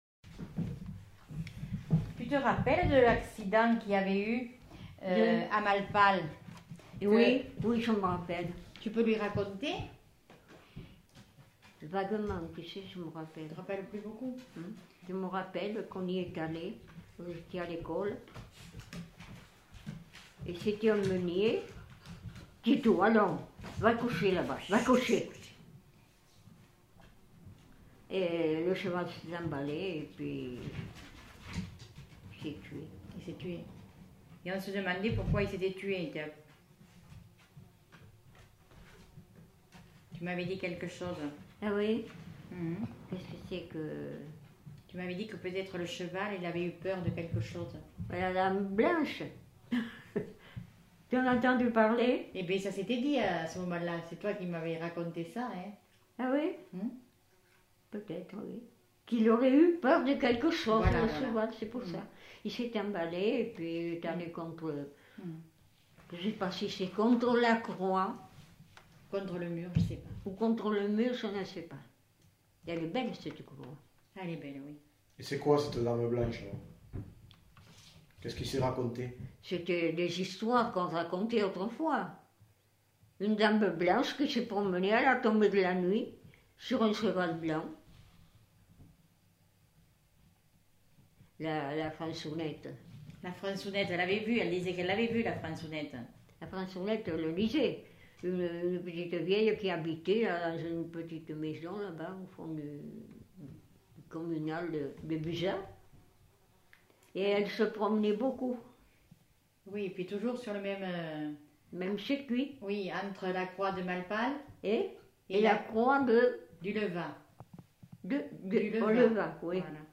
Aire culturelle : Quercy
Lieu : Gramat
Genre : conte-légende-récit
Type de voix : voix de femme
Classification : récit légendaire